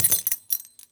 foley_keys_belt_metal_jingle_12.wav